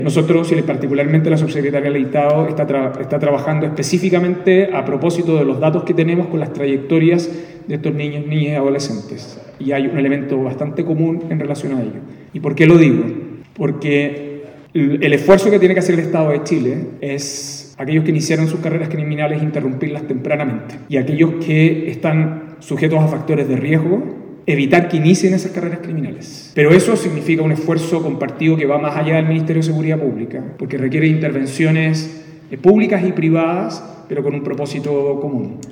El Gobierno de Chile, a través del Ministerio Secretaría General de Gobierno y el Ministerio de Educación, inició un ciclo de Diálogos Ciudadanos titulado “Hacia un nuevo Financiamiento para la Educación Superior (FES)” en la Biblioteca Municipal de Pudahuel.